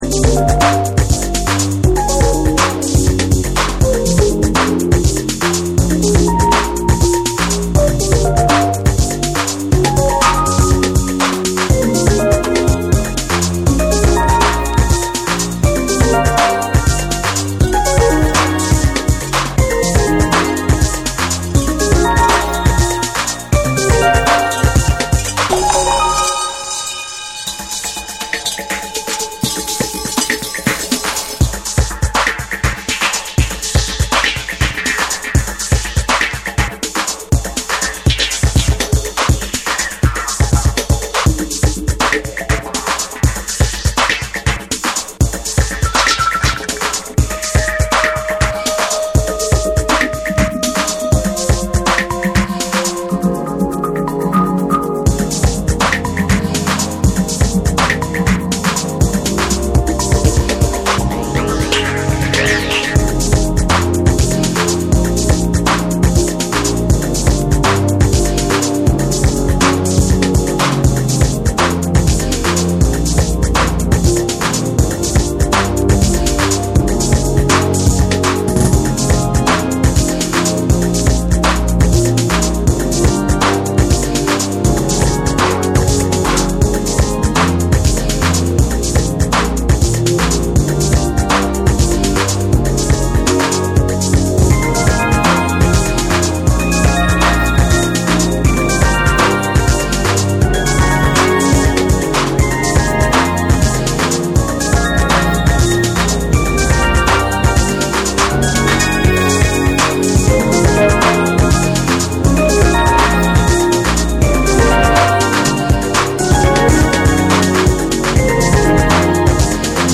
複雑に組み上げられた緻密なビートと、温かみあるメロディが共存する
流麗かつ幻想的なサウンドスケープを描く